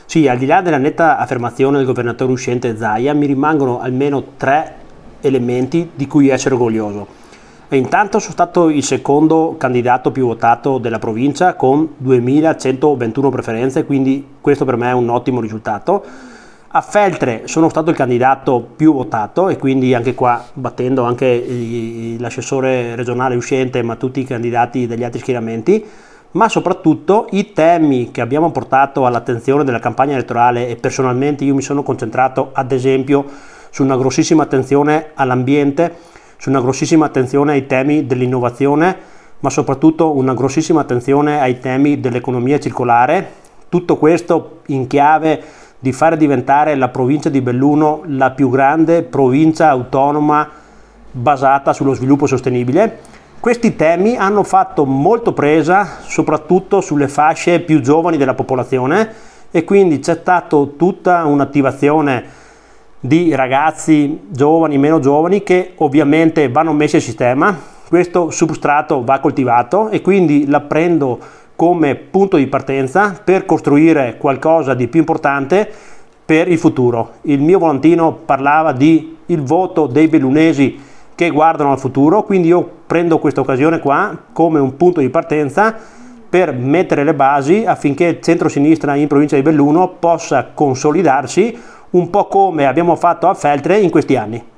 AI MICROFONI DI RADIO PIÙ